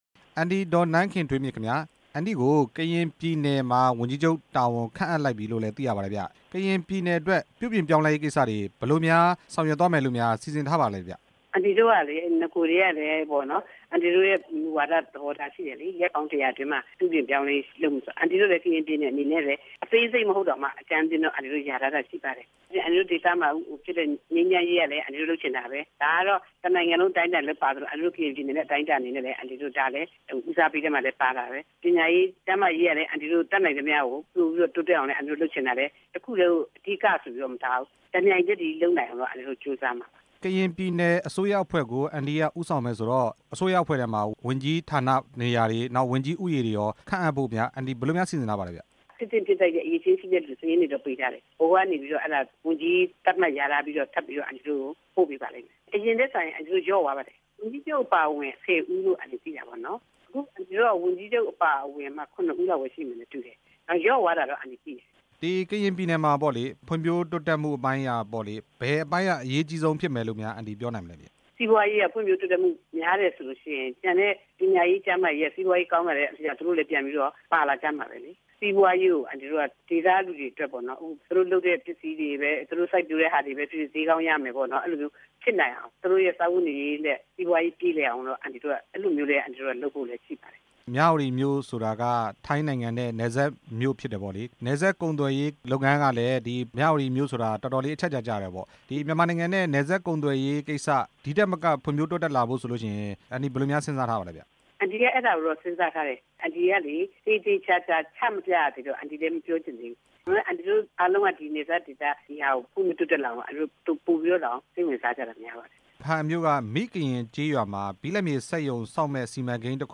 ကရင်ပြည်နယ် ဝန်ကြီးချုပ် ဒေါ်နန်းခင်ထွေးမြင့်နဲ့ မေးမြန်းချက်